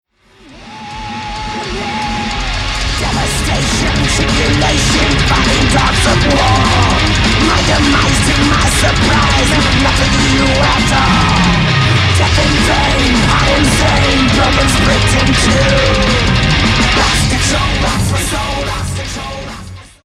The vocals sounds killer as well who is this guy ?